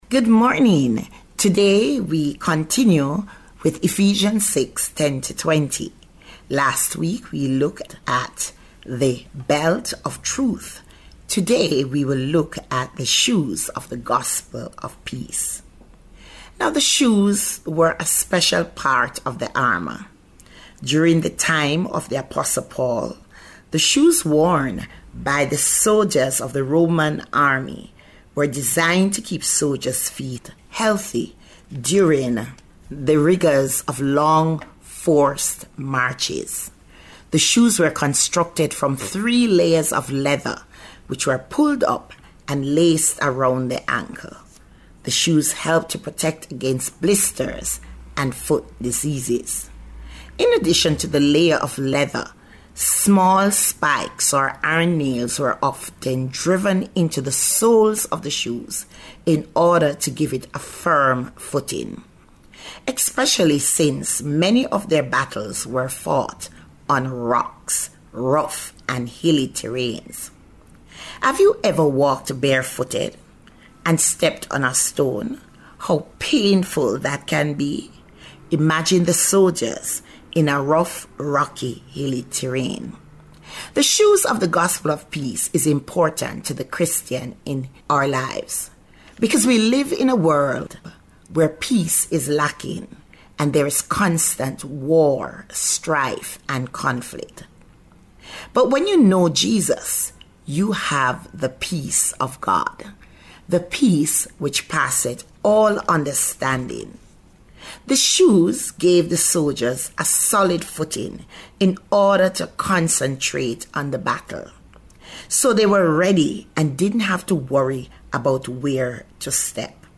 Grace Hour Broadcast